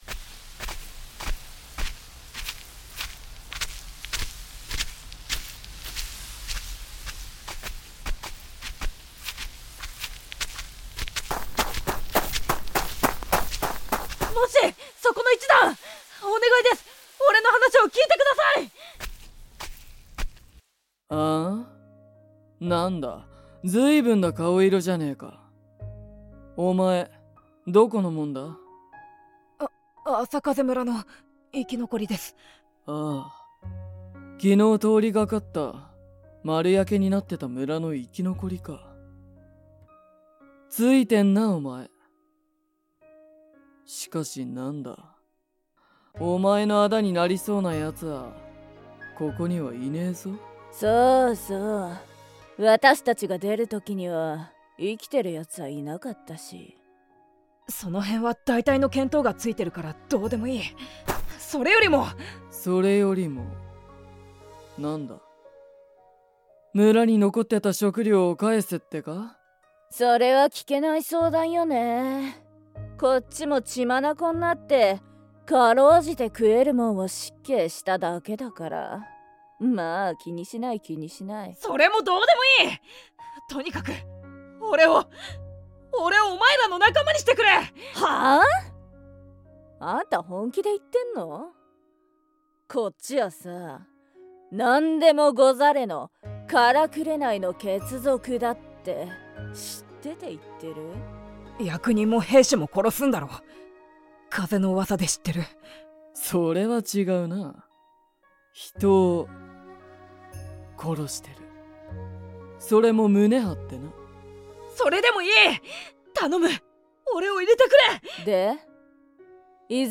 天儀異聞録・前日譚「唐紅の血族」ボイスドラマ
◆声の出演（敬称略）